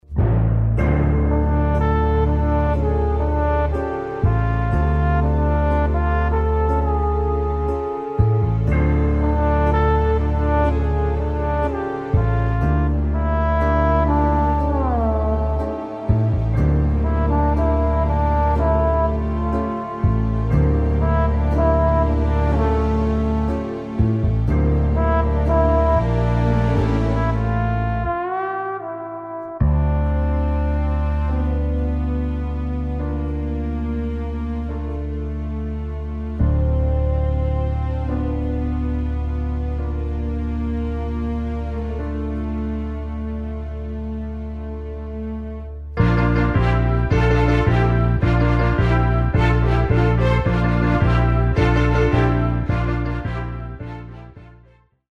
Instrumental
backing track